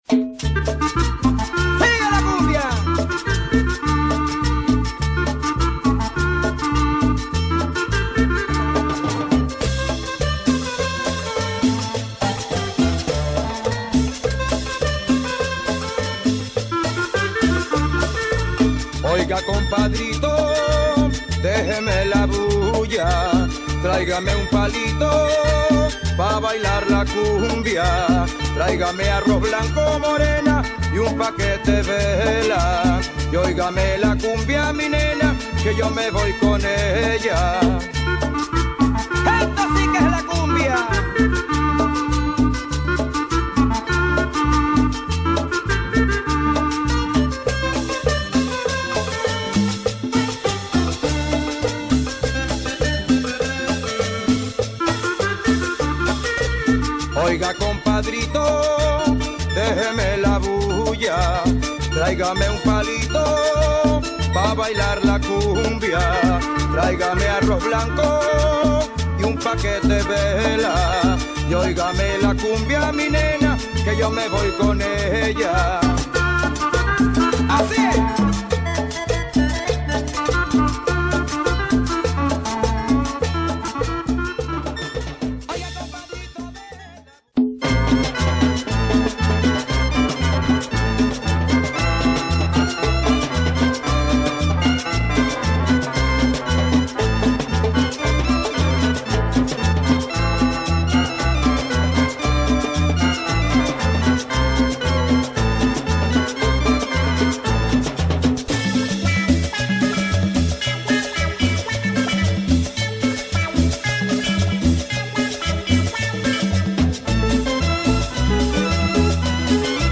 Some great cumbia tunes from Venezuela